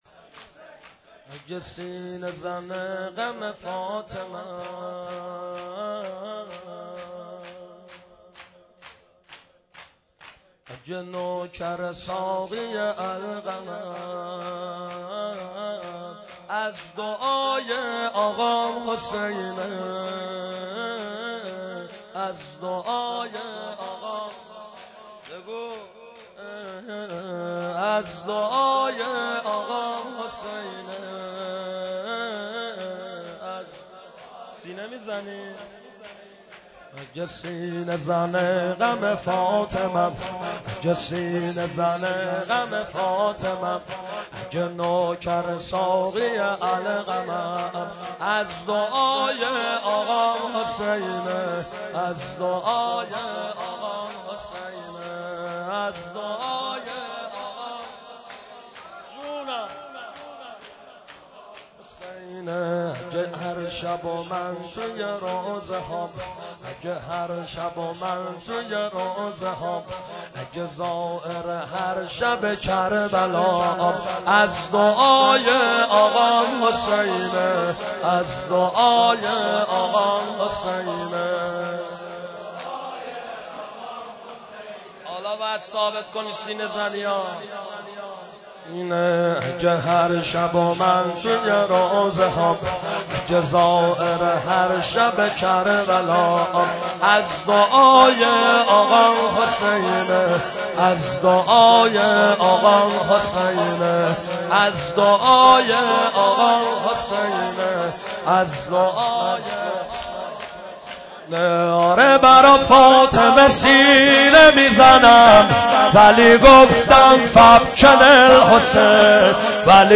فاطمیه93 هیئت امام موسی کاظم برازجان